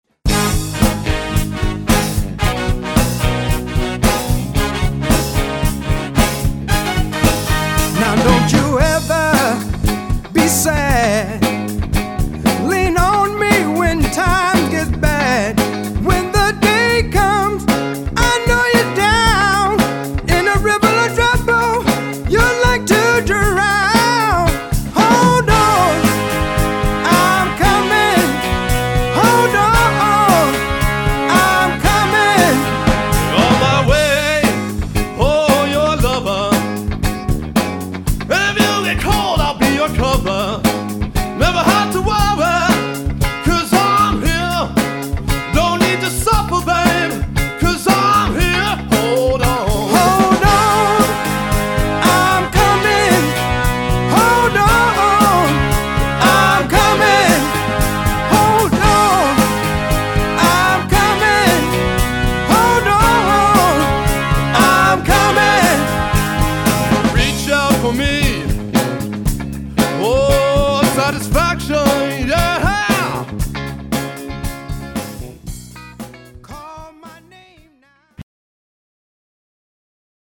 classic soul and funk music from the 60s and 70s